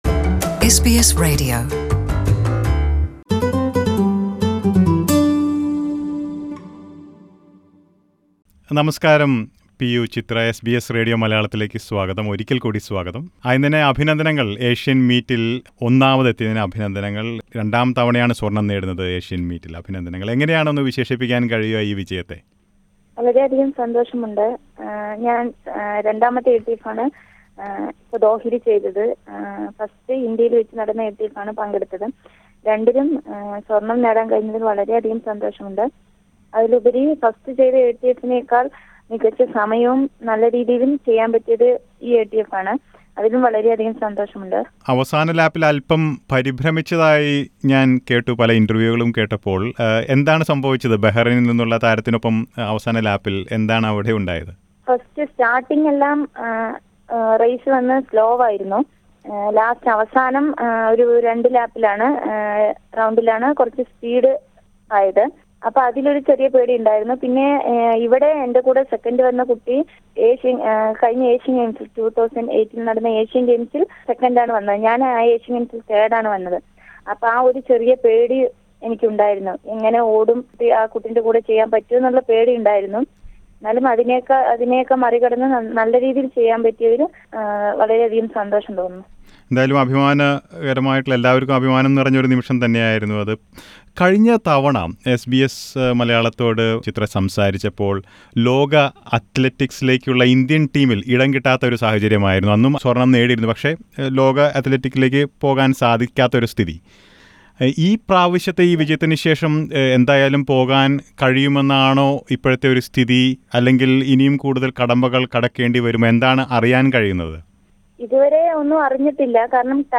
Indian athlete P U Chitra shares her joy with SBS Malayalam after winning gold in the 1500-meters race at the Asian Athletic Championships in Doha.